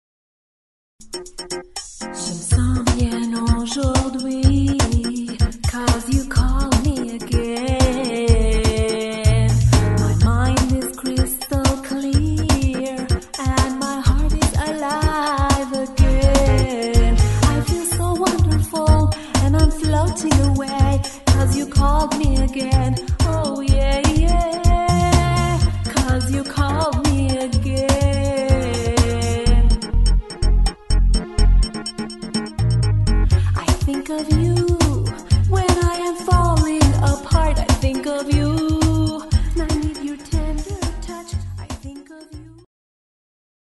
Category: Pop